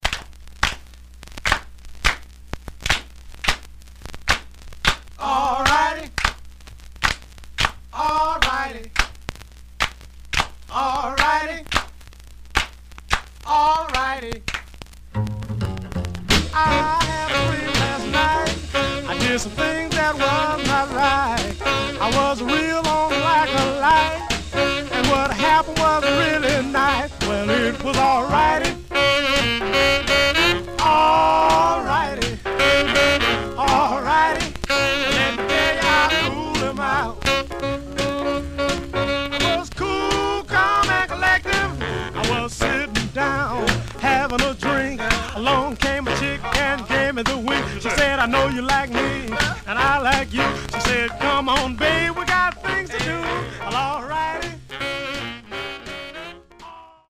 Some surface noise/wear
Mono
Male Black Groups